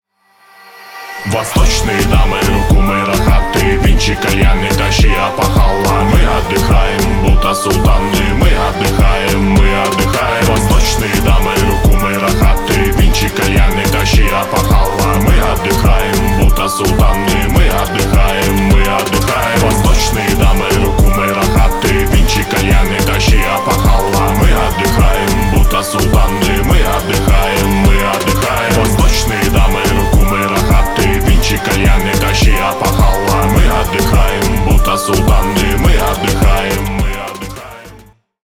Рэп и Хип Хоп
восточные